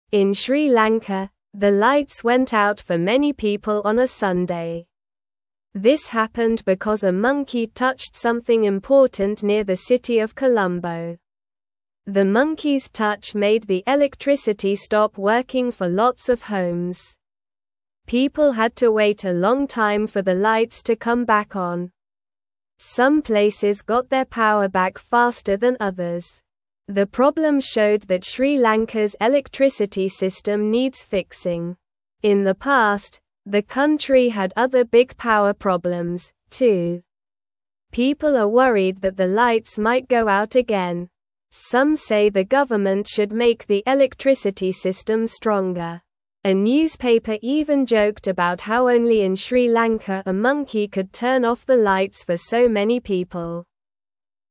Slow
English-Newsroom-Beginner-SLOW-Reading-Monkey-Turns-Off-Lights-in-Sri-Lanka-for-Everyone.mp3